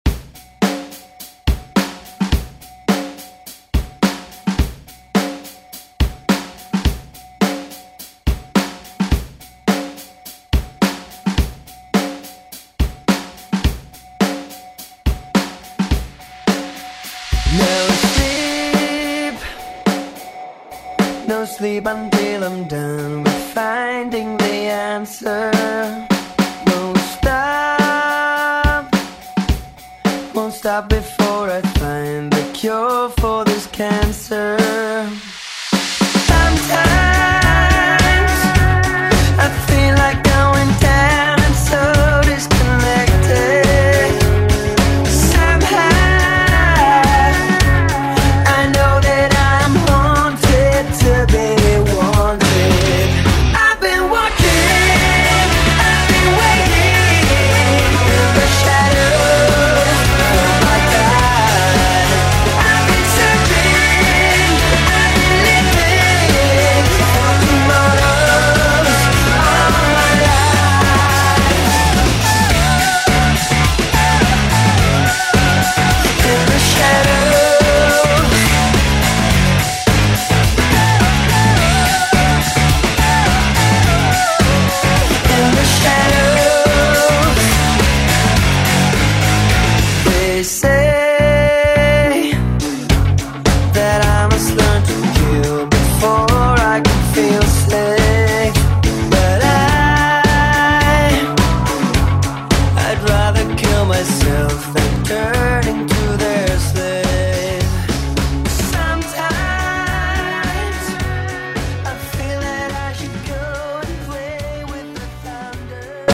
Throwback Hip Hop Rap Music
Extended Intro Outro
107 bpm